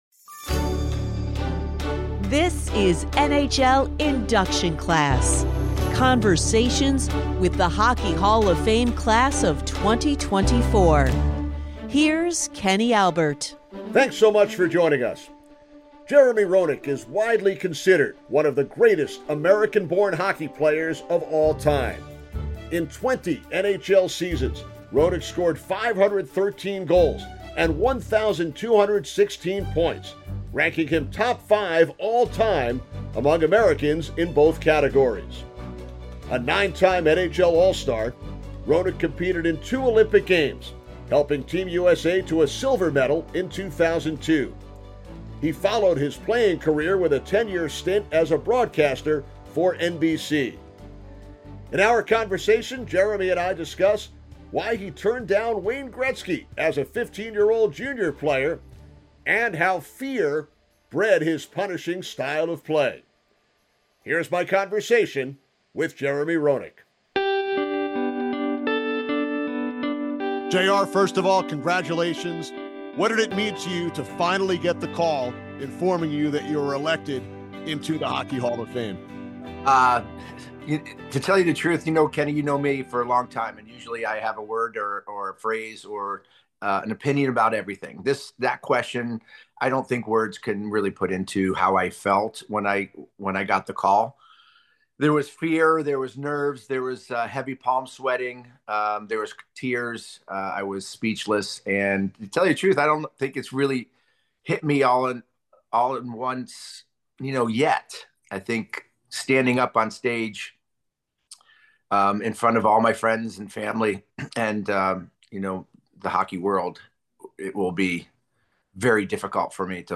Headliner Embed Embed code See more options Share Facebook X Subscribe Nine-time NHL all-star Jeremy Roenick is Kenny Albert’s guest. Roenick discusses his meeting with Wayne Gretzky as a 15-year-old, memories from his NHL debut as a teenager, relationships with his most notable former coaches, how “fear” bred his punishing style of play, and the pinnacle of his hockey career.